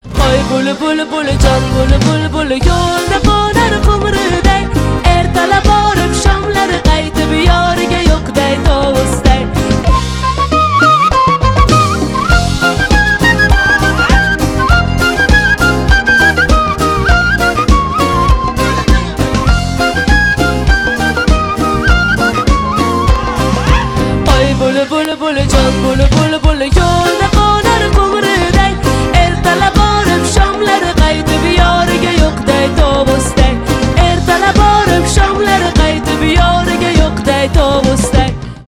восточные , узбекские , поп